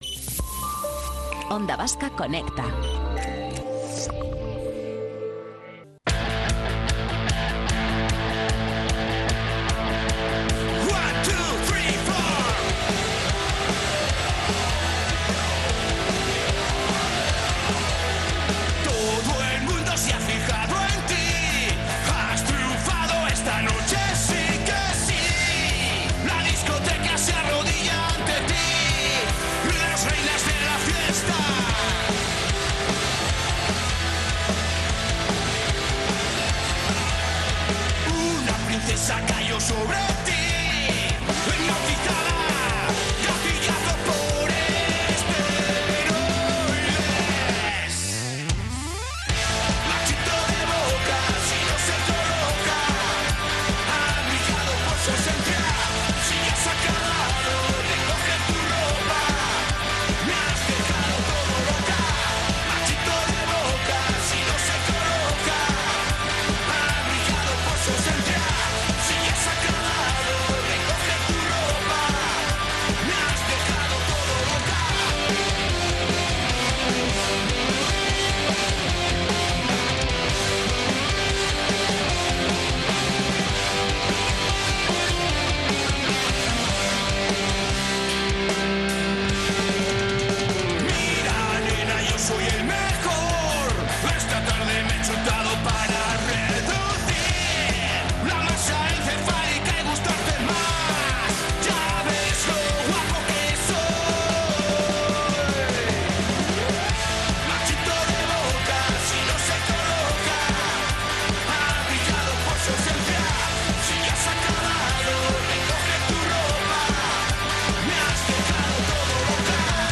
Compañía y entretenimiento con los oyentes en el centro.